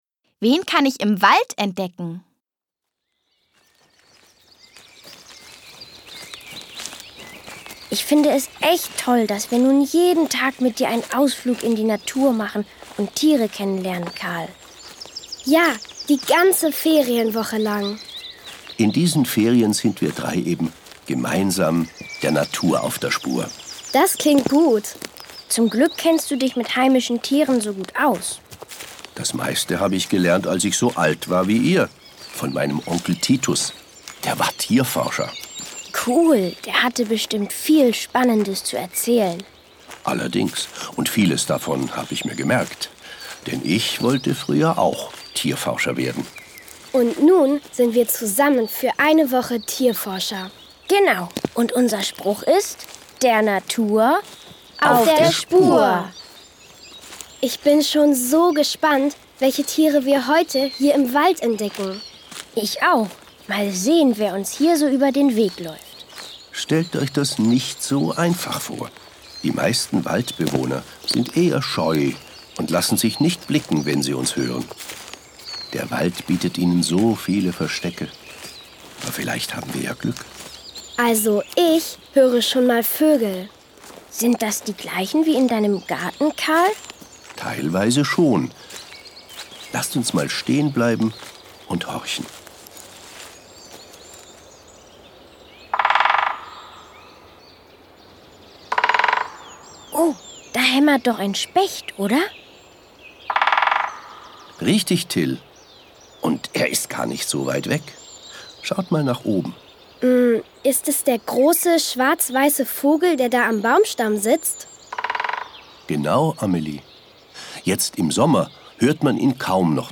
Hörbuch: Wieso?